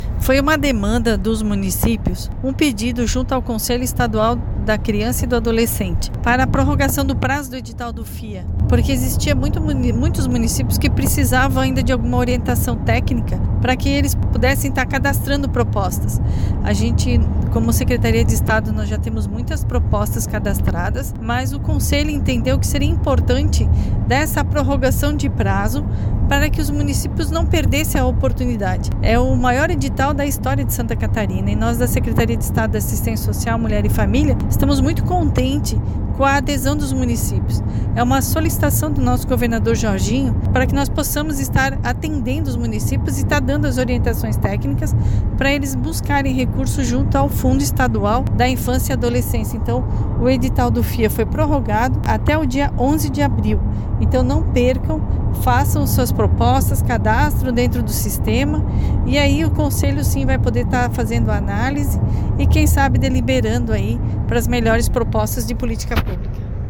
A secretária de estado da Assistência Social, Mulher e Família, Maria Helena Zimmermann, explica que a prorrogação do prazo vem após pedido de alguns municípios que precisavam de orientação: